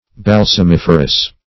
Search Result for " balsamiferous" : The Collaborative International Dictionary of English v.0.48: Balsamiferous \Bal`sam*if"er*ous\ (b[add]l`sam*[i^]f"[~e]r*[u^]s or b[a^]l`sam*[i^]f"[~e]r*[u^]s), a. [Balsam + -ferous.]
balsamiferous.mp3